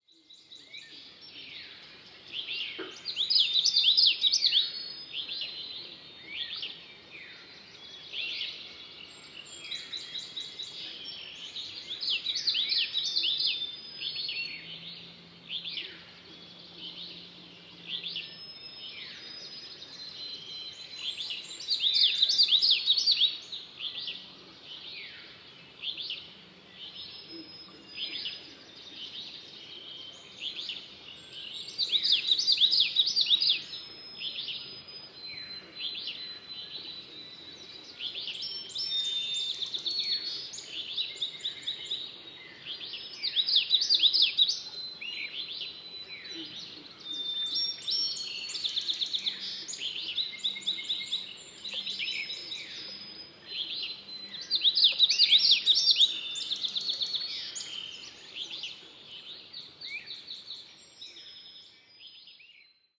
Common Yellowthroat – Geothlypis trichas
Song Eastern Townships, QC.